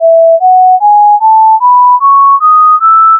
Also, listen to a audio clip of the E major scale so you can listen to the sound.
emajor.mp3